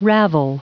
Prononciation du mot ravel en anglais (fichier audio)
Prononciation du mot : ravel